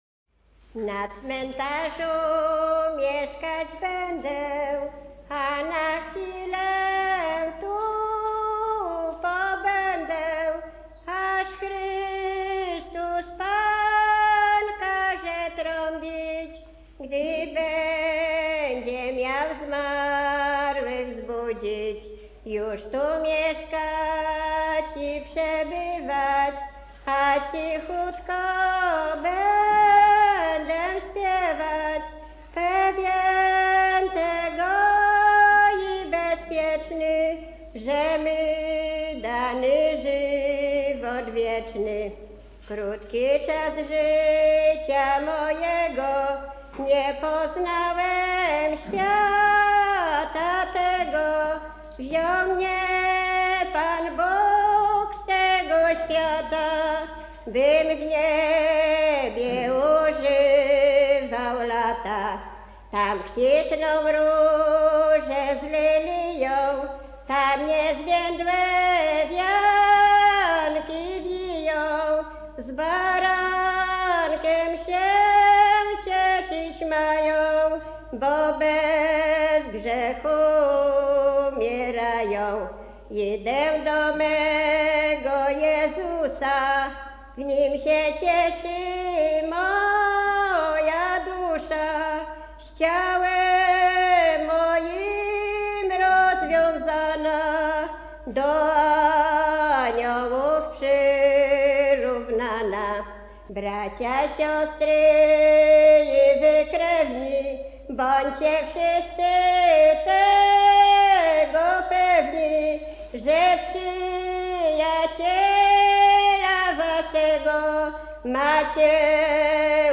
województwo mazowieckie, powiat garwoliński, gmina Trojanów, wieś Wola Korycka
Pogrzebowa
pogrzebowe katolickie nabożne